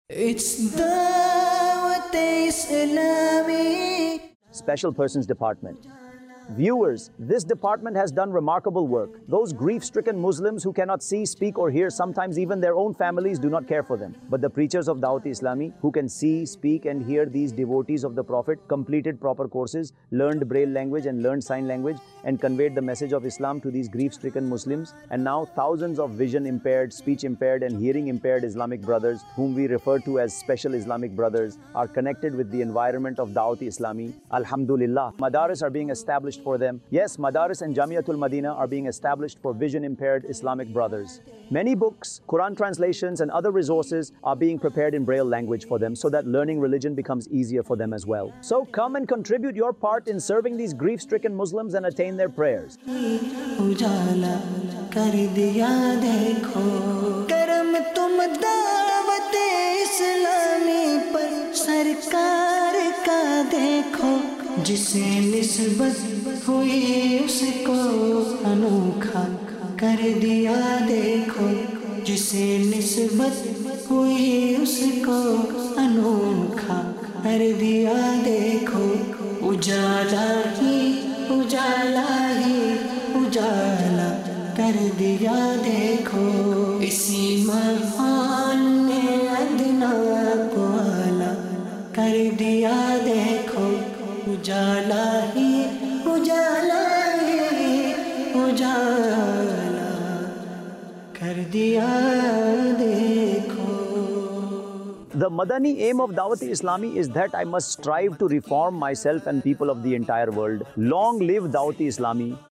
Special Persons Department | Department of Dawateislami | Documentary 2025 | AI Generated Audio Mar 22, 2025 MP3 MP4 MP3 Share مجلسِ خصوصی اسلامی بھائی | شعبہِ دعوت اسلامی | ڈاکیومینٹری 2025 | اے آئی جنریٹڈ آڈیو